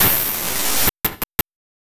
Abstract Rhythm 40.wav